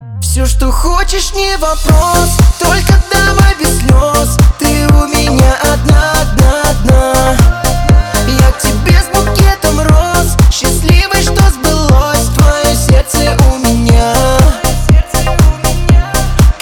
Медленные
Поп